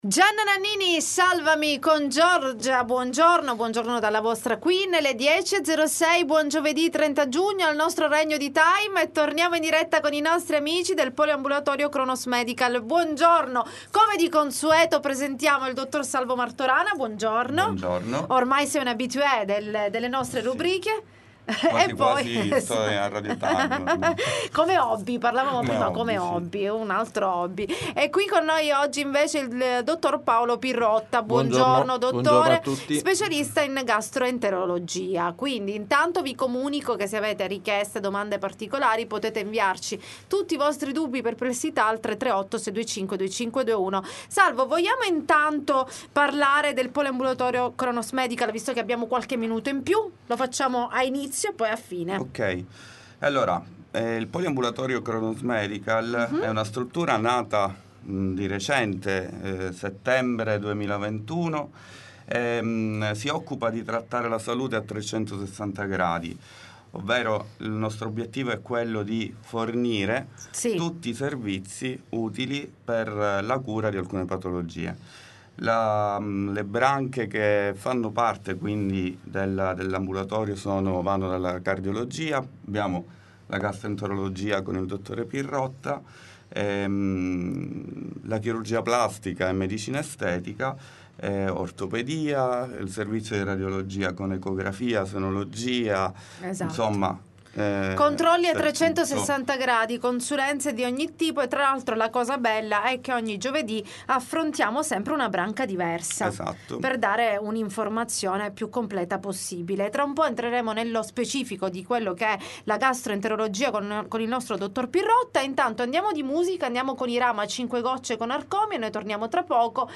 L.T. Intervista Kronos Medical